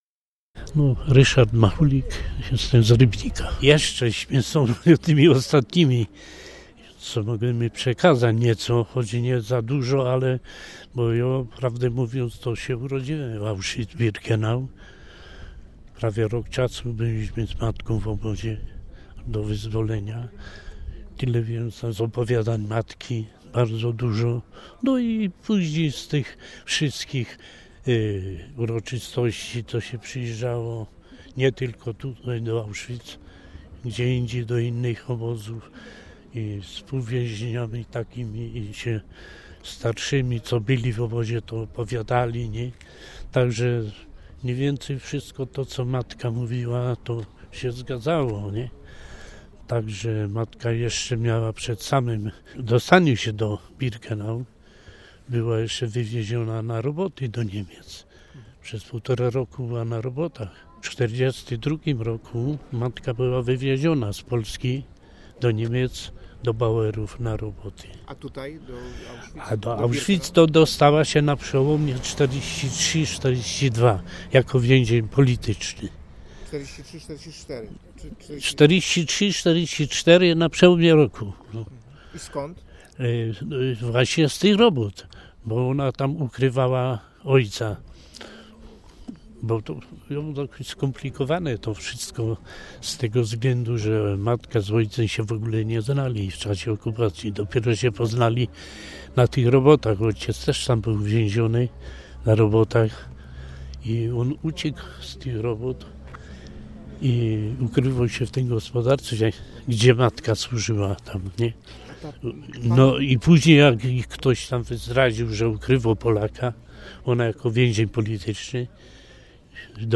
O tym, że myślenie pomysłodawców obozów zagłady było na antypodach nauki Jezusa przypomniał podczas homilii w kościele franciszkańskim bp Roman Pindel, gdzie 14 czerwca sprawował Mszę św. z okazji 78. rocznicy pierwszego transportu polskich więźniów politycznych do KL Auschwitz.
auschwitz_uroczytosci_14-czerwca.mp3